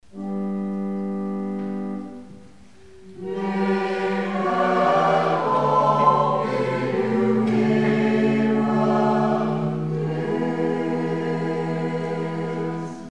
afgewisseld met het zingen van